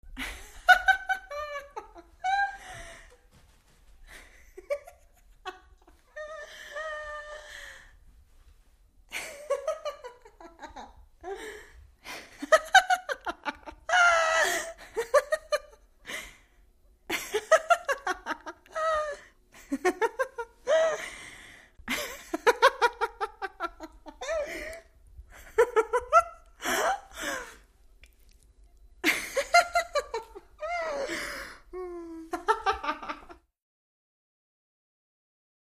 Single Woman Laughs, Medium Perspective.